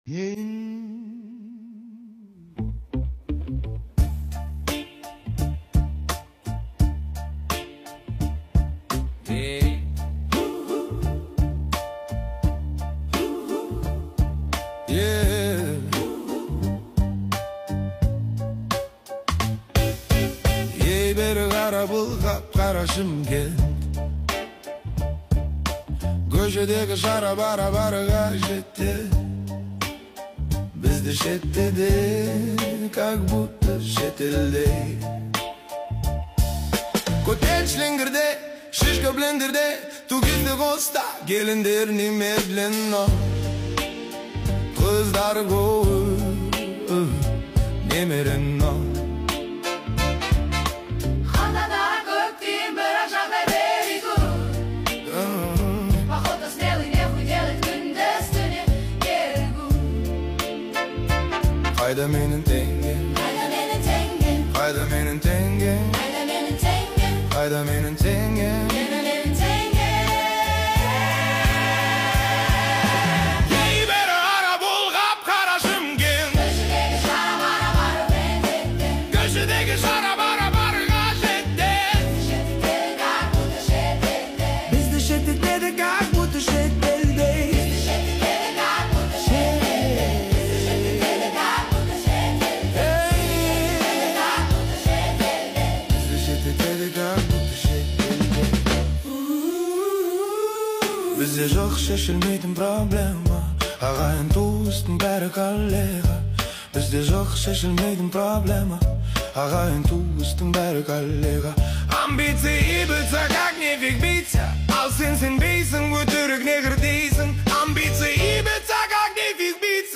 AI Cover 1950's Jazz Soul Version